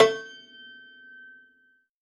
53w-pno11-G4.wav